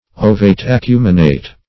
Search Result for " ovate-acuminate" : The Collaborative International Dictionary of English v.0.48: Ovate-acuminate \O"vate-a*cu"mi*nate\, a. Having an ovate form, but narrowed at the end into a slender point.